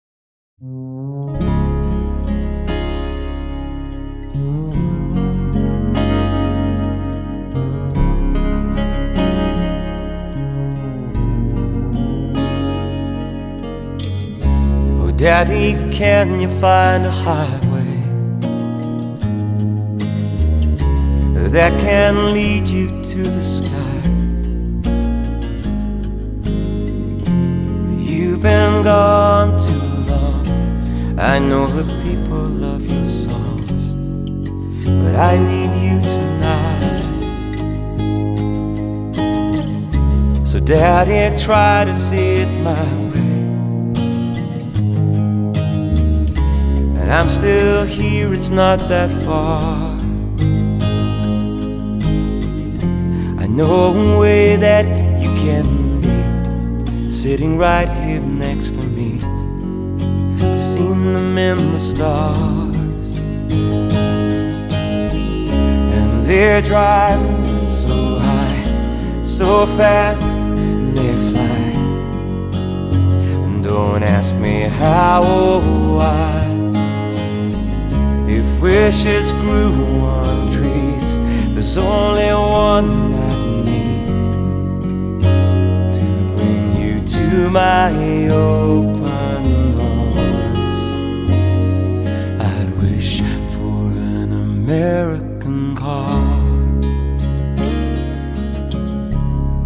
發燒天碟